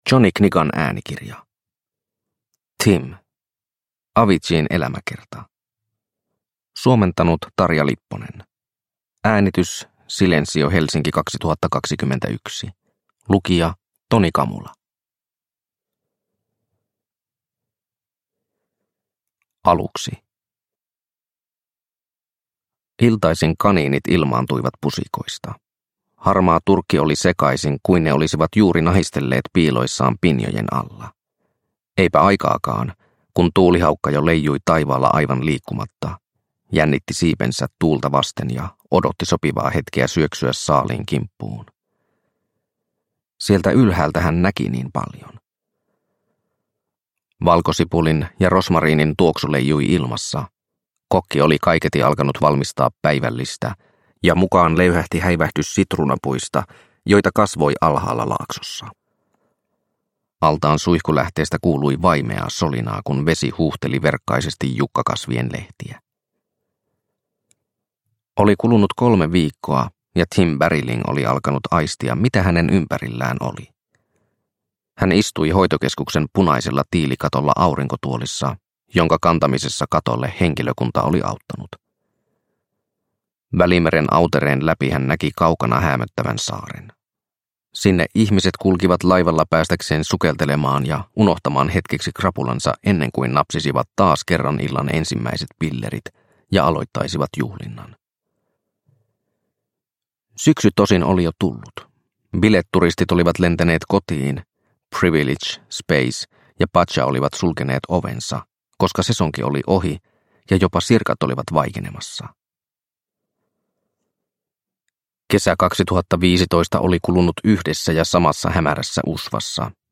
Tim – Aviciin elämäkerta – Ljudbok – Laddas ner